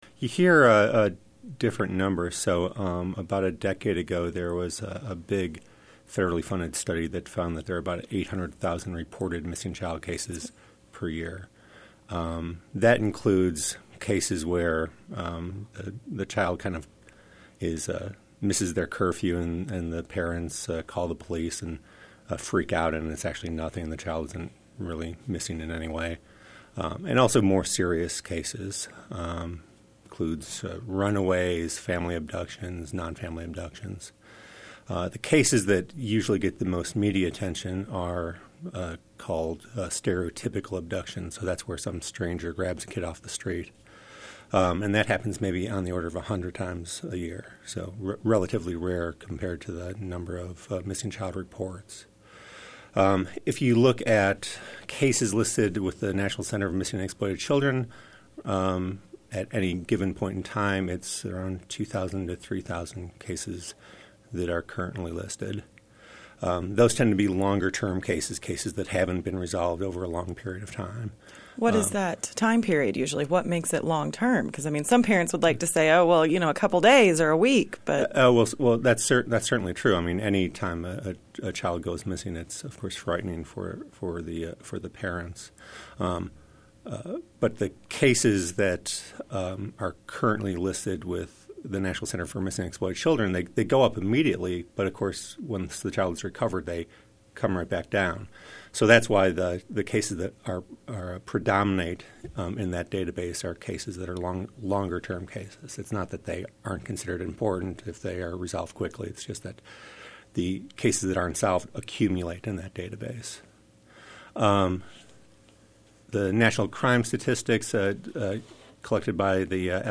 a member of the research team.